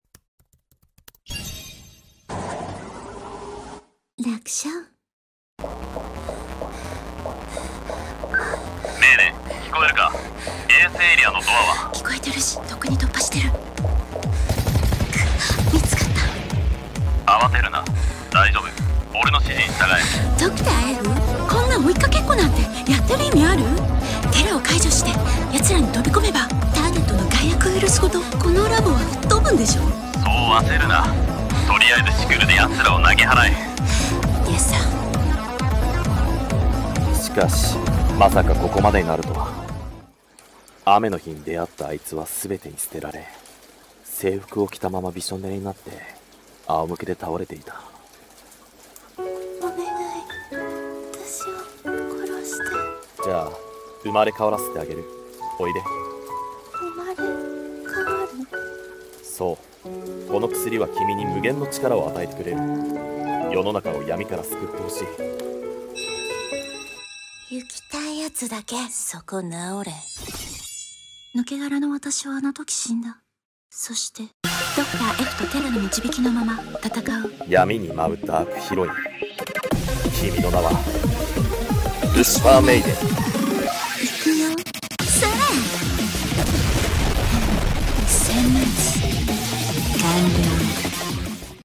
Lucifer Maiden 【二人声劇